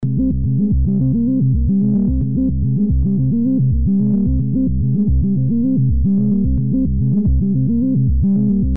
管风琴声音 " 失真管风琴导音
描述：器官通过Hammond Sounder III器官的金属变形失真踏板。
标签： 扭曲 琶音 污垢 重复
声道立体声